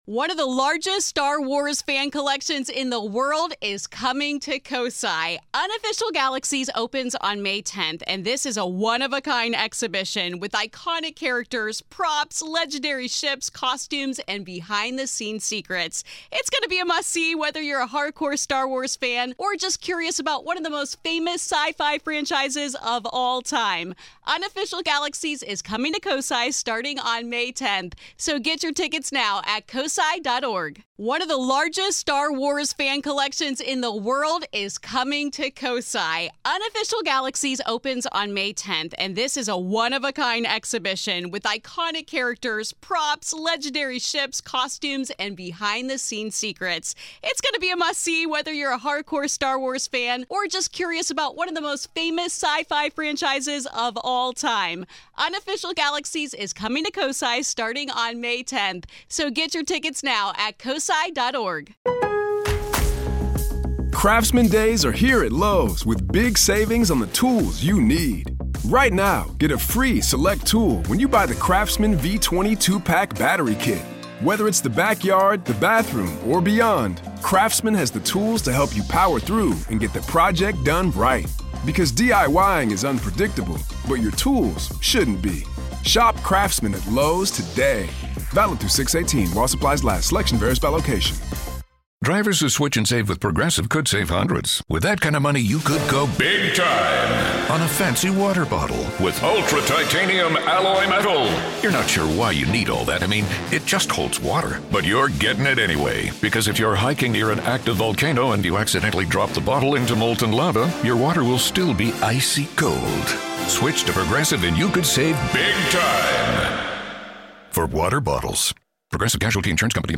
talks with comedians, actors, and filmmakers about horror movies!